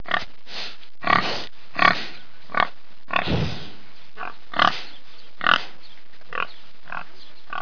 Categoria Animali